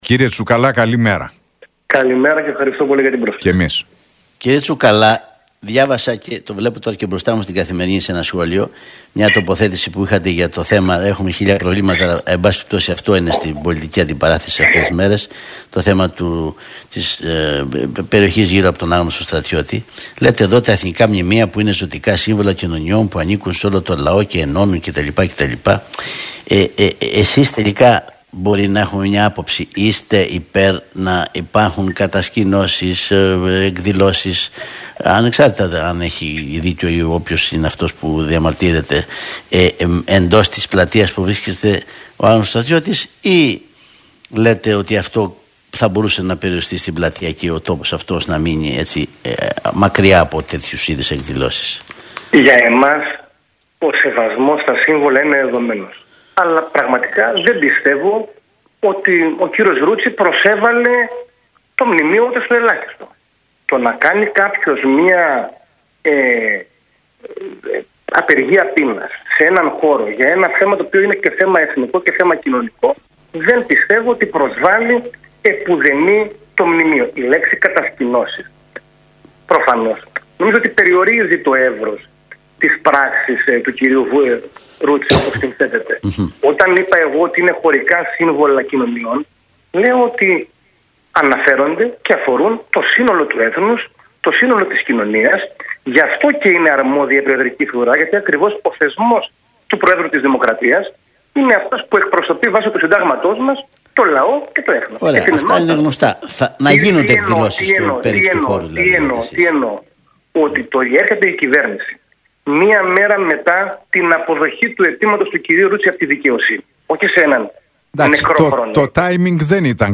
Για το θέμα που έχει προκύψει με το Μνημείο του Αγνώστου Στρατιώτη και το εργασιακό νομοσχέδιο, που συζητείται στην Βουλή, μίλησε ο εκπρόσωπος Τύπου του ΠΑΣΟΚ, Κώστας Τσουκαλάς στον Νίκο Χατζηνικολάου και τον Αντώνη Δελλατόλα από τη συχνότητα του Realfm 97,8.